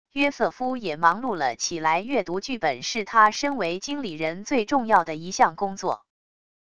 约瑟夫也忙碌了起来――阅读剧本是他身为经理人最重要的一项工作wav音频生成系统WAV Audio Player